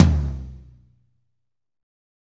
Index of /90_sSampleCDs/Northstar - Drumscapes Roland/DRM_Pop_Country/KIT_P_C Wet 1 x